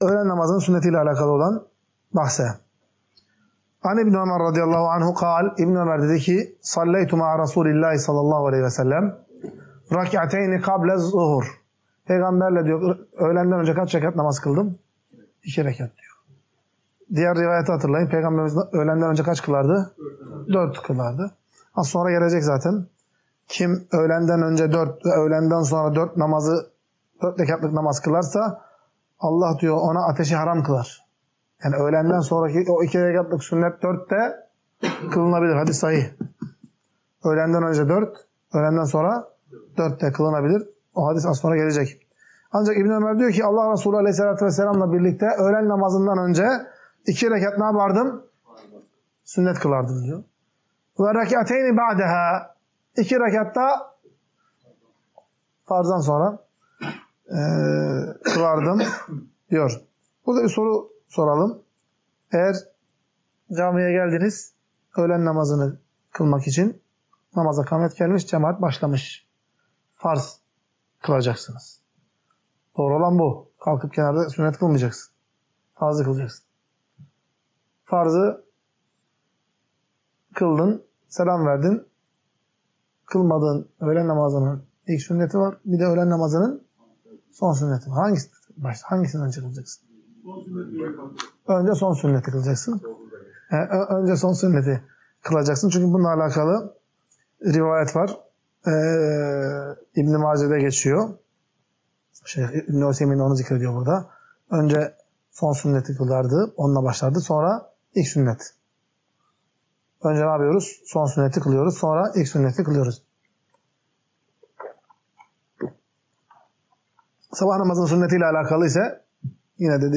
Ders - 20.